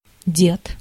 Ääntäminen
UK : IPA : /ˈɡɹændˌfɑːðə(ɹ)/